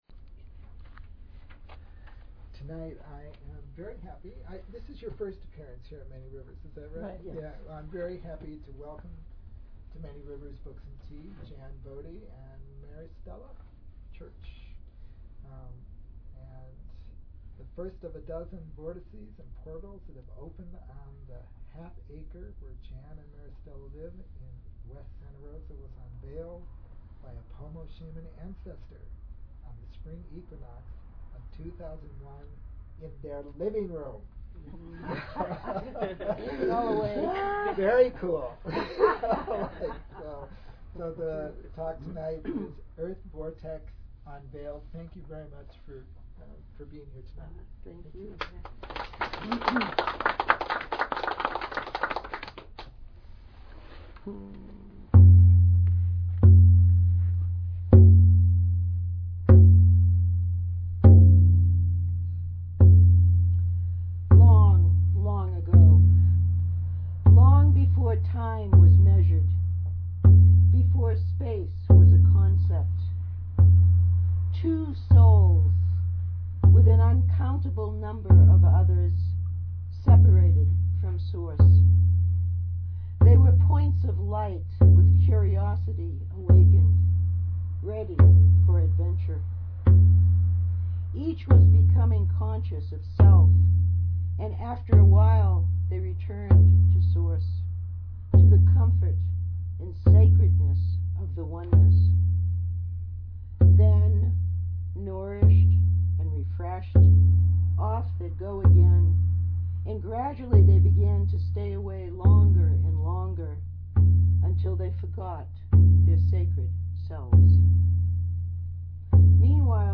Archive of an event at Sonoma County's largest spiritual bookstore and premium loose leaf tea shop.